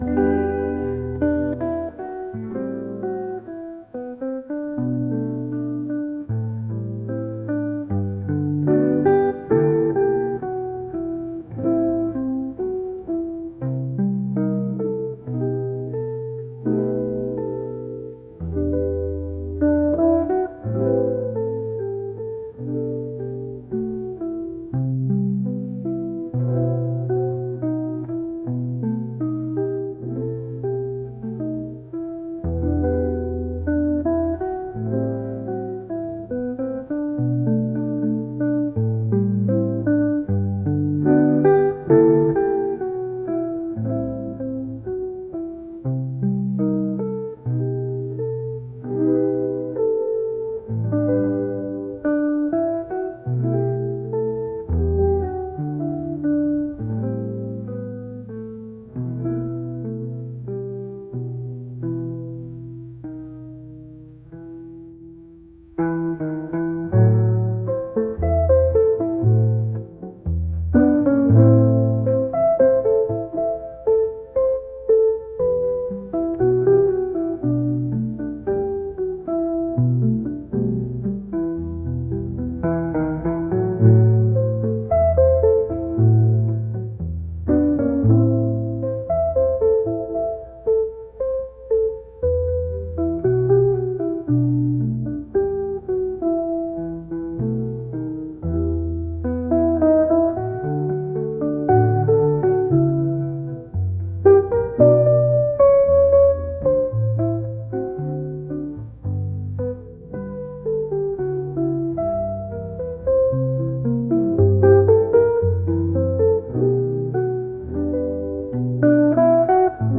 jazz | lounge | acoustic